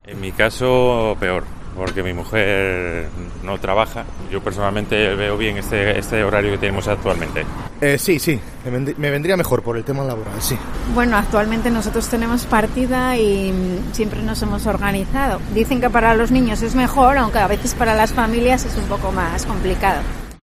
Los padres opinan sobra el horario escolar